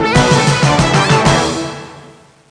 1 channel
BELL.mp3